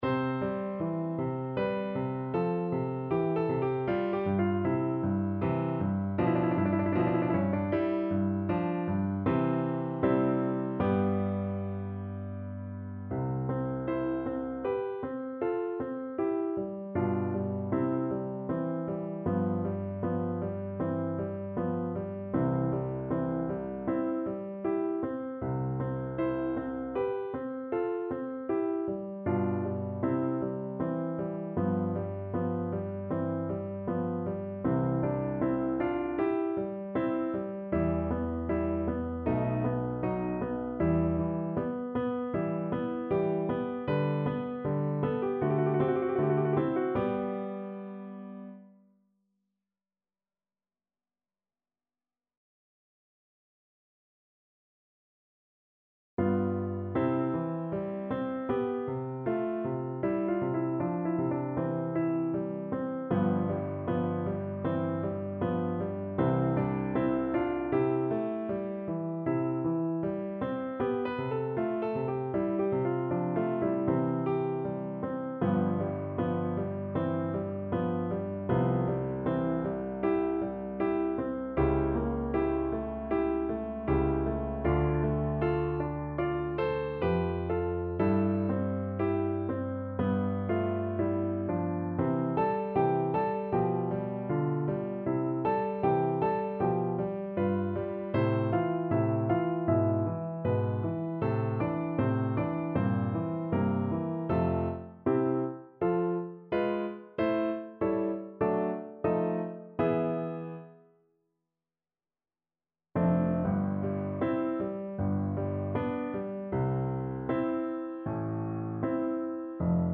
Play (or use space bar on your keyboard) Pause Music Playalong - Piano Accompaniment Playalong Band Accompaniment not yet available reset tempo print settings full screen
C major (Sounding Pitch) (View more C major Music for Tuba )
Larghetto cantabile =39
Classical (View more Classical Tuba Music)